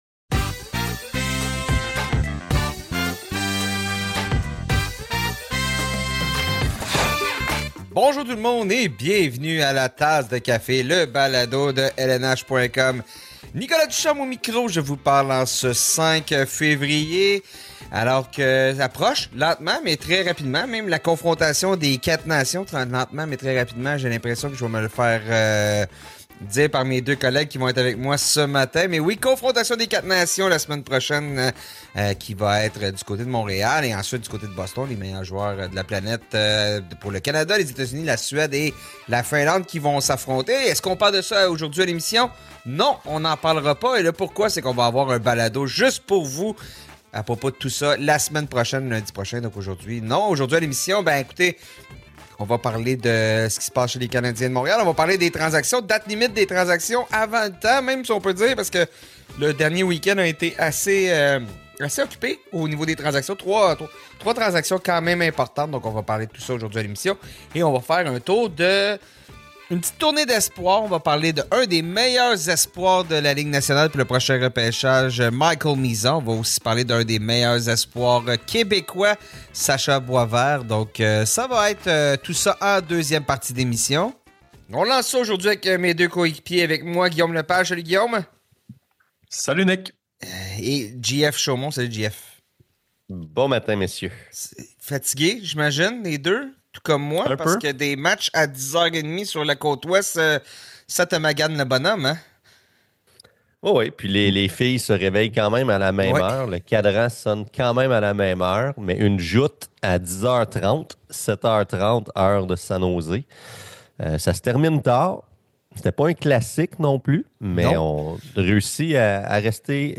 Les trois journalistes parlent aussi du parcours atypique de Jakub Dobes, qui a dû quitter son pays à 15 ans pour progresser dans le hockey et finalement se rendre dans la LNH avec les Canadiens. L’équipe revient aussi sur les trois importantes transactions qui ont retenu l’attention dans les derniers jours, dont celle qui a envoyé J.T. Miller chez les Rangers de New York.